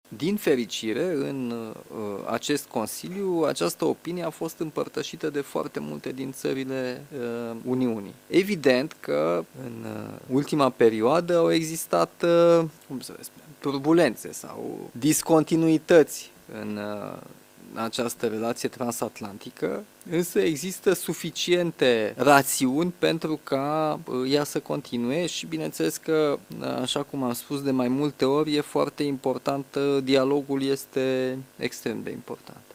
Pentru România, Statele Unite reprezintă un partener strategic esențial pentru securitatea țării și acționăm în consecință, a declarat președintele Nicușor Dan, aseară, după Consiliul European informal de la Bruxelles.